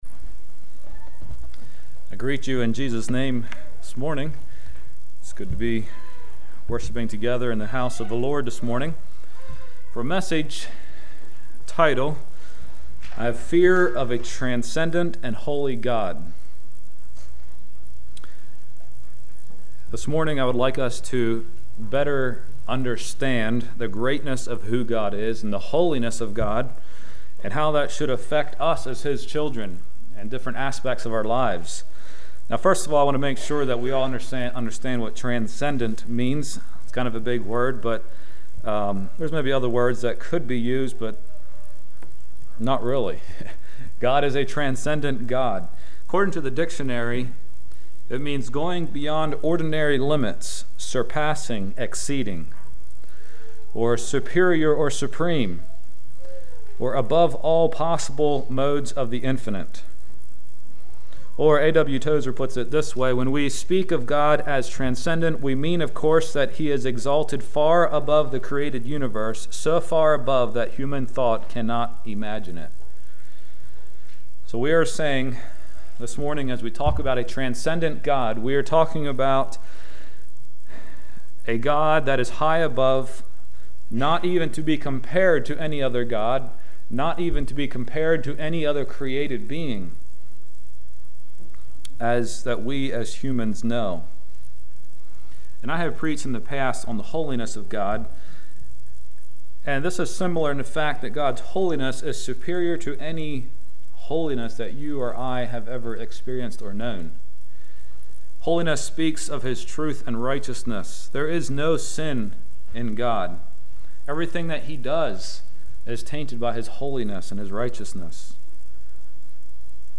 Sermons
Blue Ridge | All Day Meetings 2024